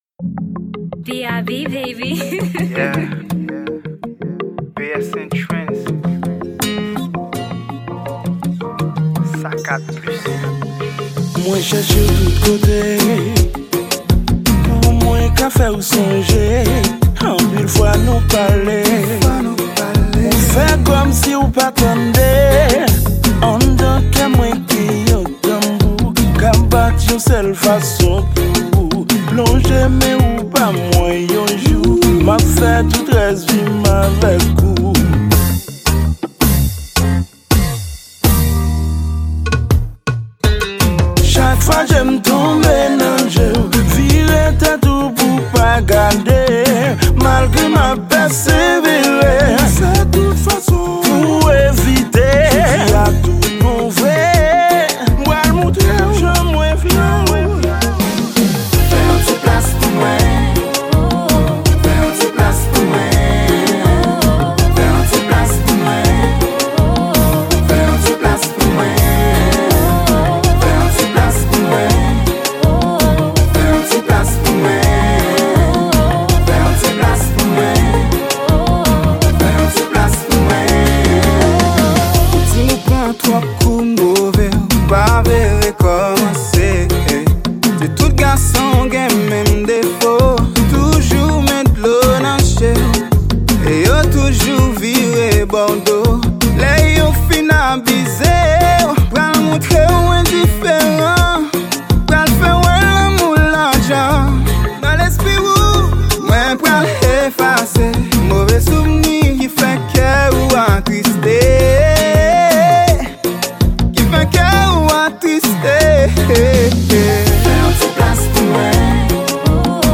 Genre: Zouk.